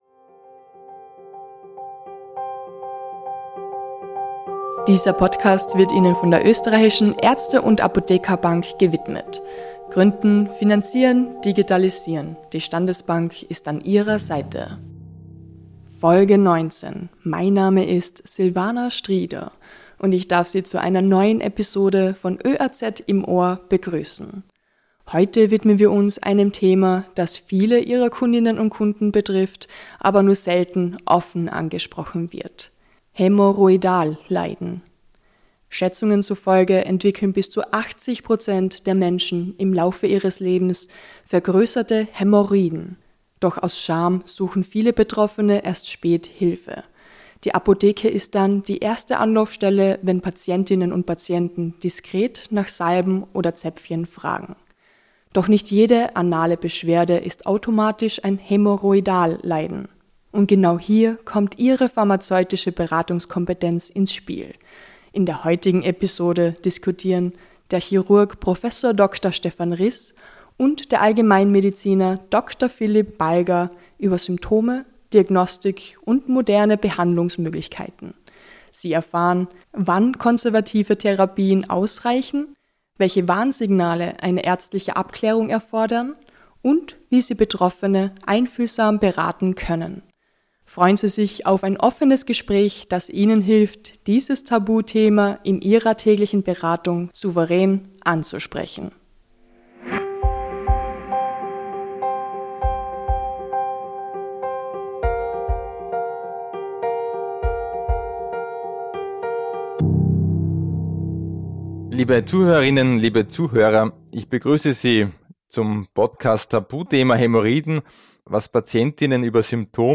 Sie erfahren, wann konservative Therapien ausreichen, welche Warnsignale eine ärztliche Abklärung erfordern und wie Sie Betroffene einfühlsam beraten können. Freuen Sie sich auf ein offenes Gespräch, das Ihnen hilft, dieses Tabuthema in Ihrer täglichen Beratung souverän anzusprechen.